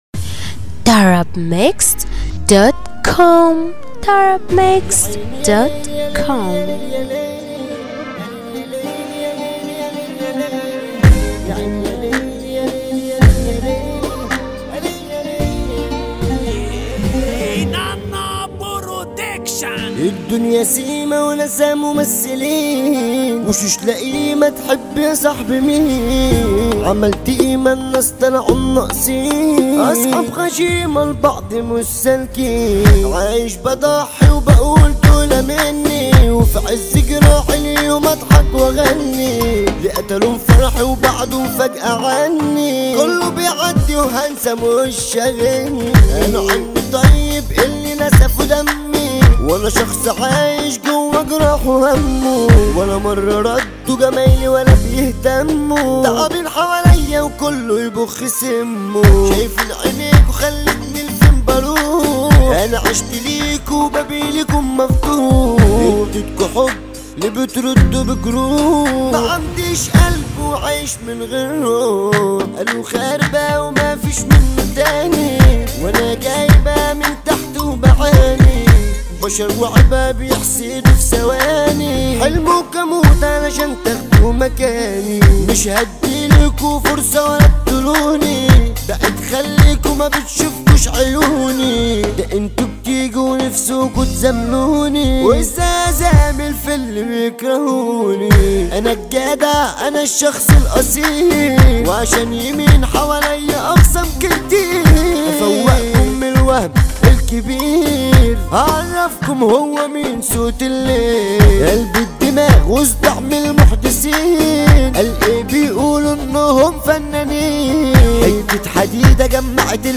• النوع : festival